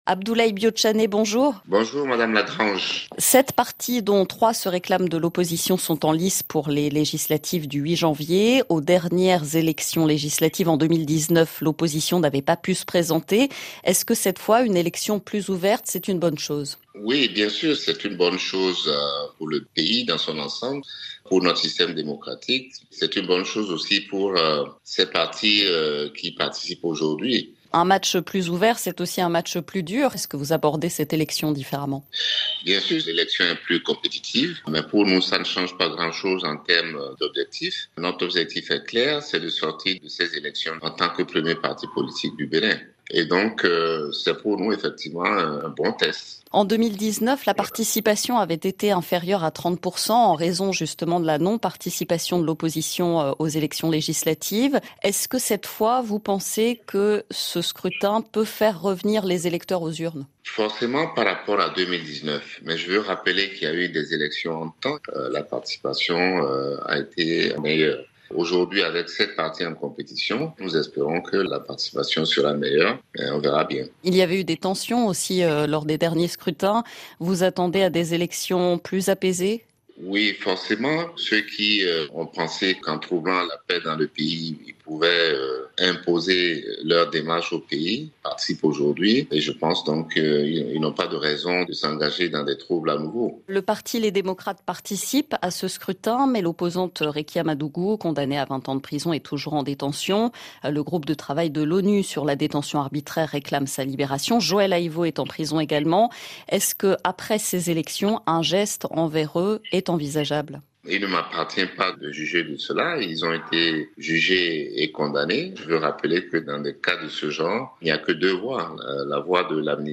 Entretien avec Abdoulaye Bio Tchané, secrétaire général national du parti Bloc Républicain (BR).